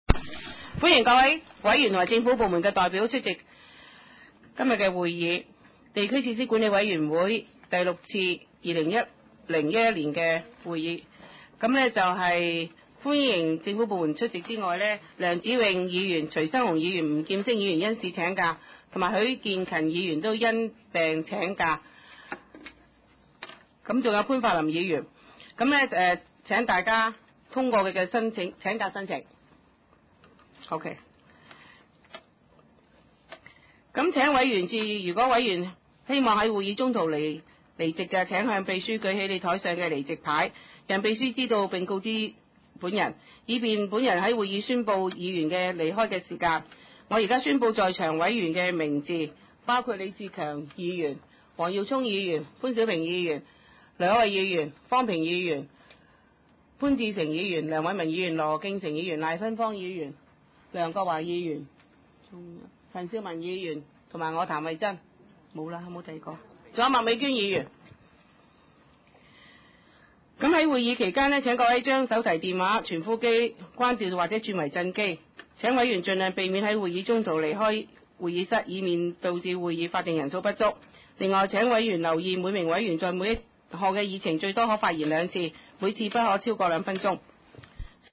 第六次會議(一零/一一)
葵青民政事務處會議室
開會詞